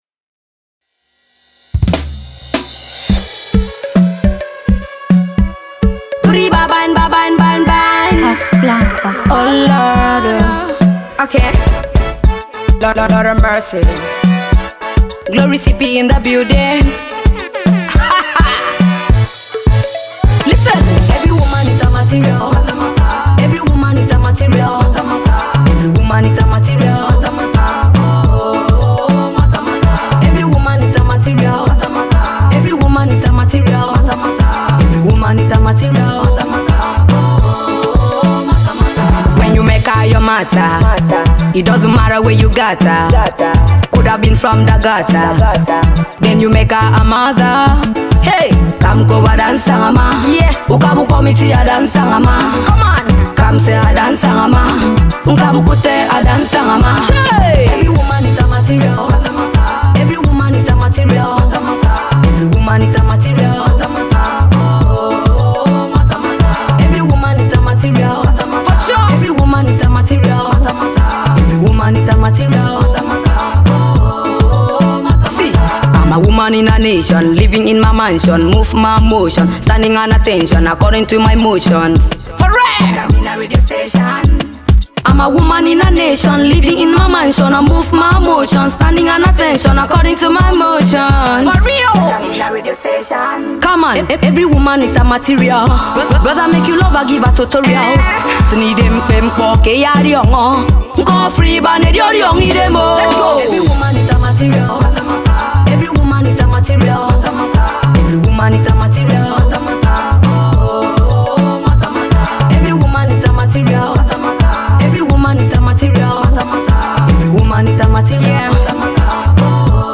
hip hop-reggae
catchy delivery